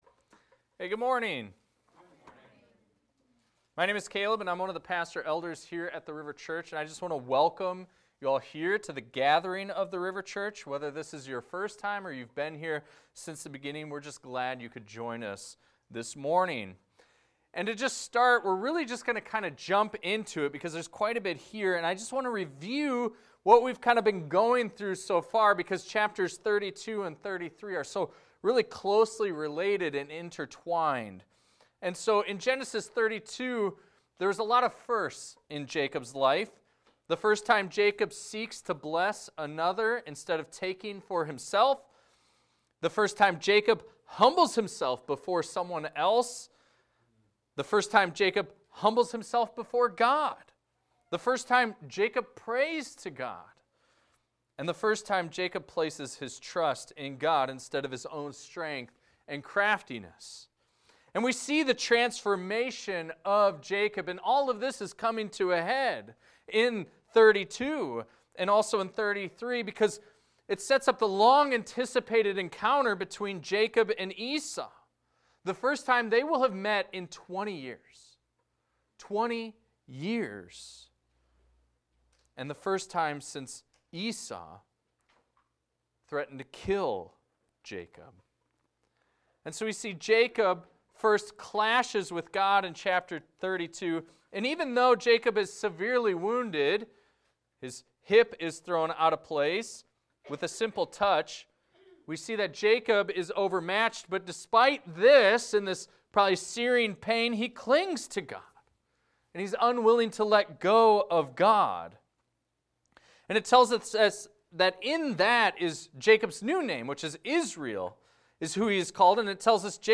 This is a recording of a sermon titled, "Unwavering Faith."